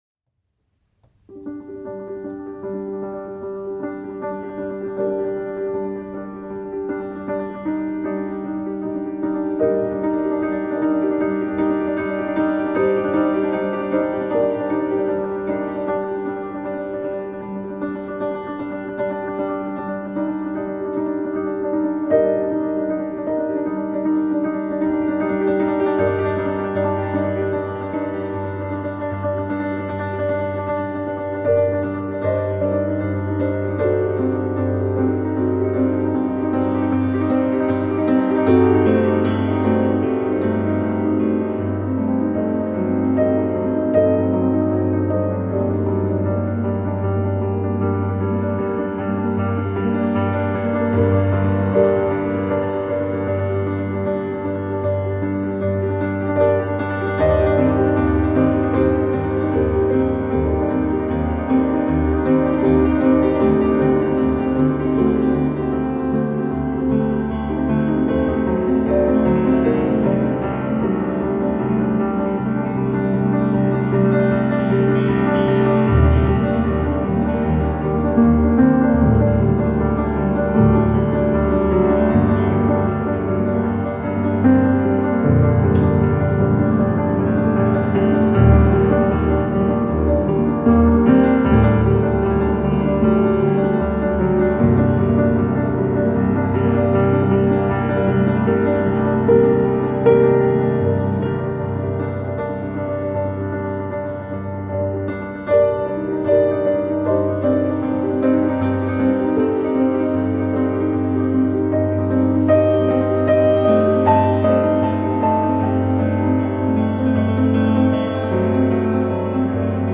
instrumental
دانلود آهنگ پیانو
موسیقی بیکلام